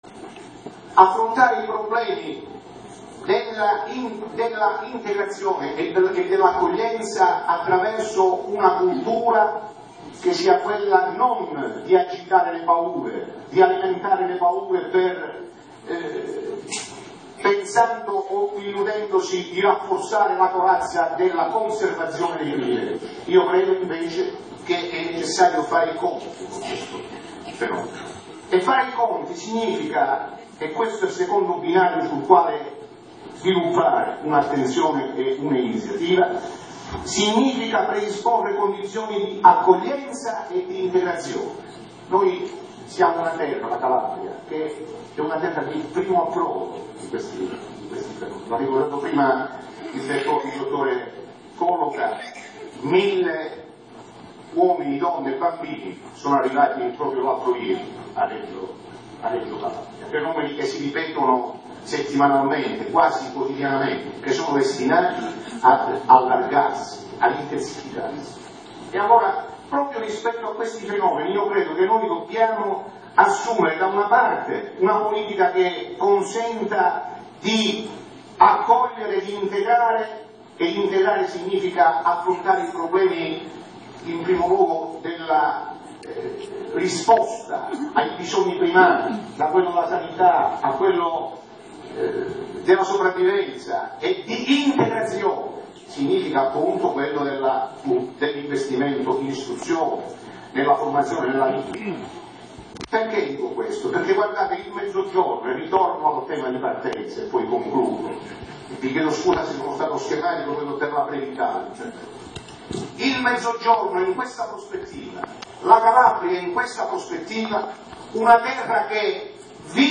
Poi c’è stato il significativo saluto portato dal